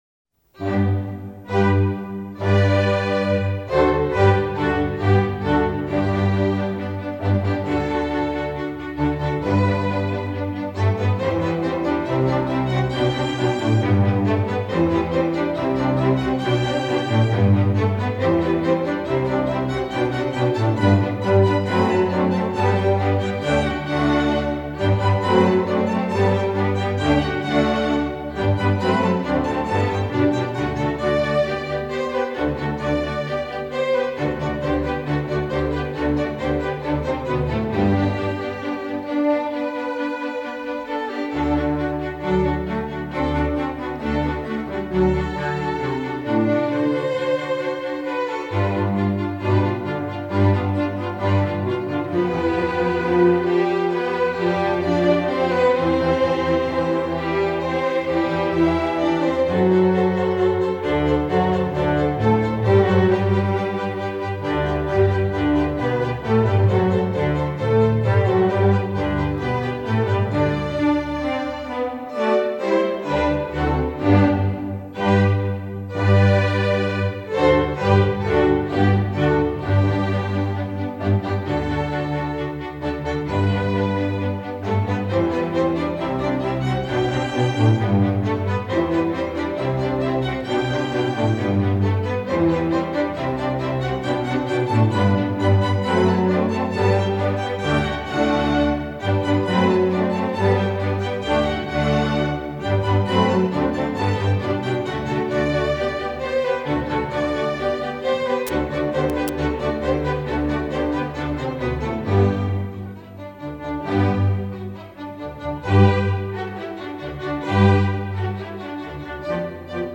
Instrumentation: string orchestra
classical, children